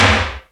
HARD SNAP SD.wav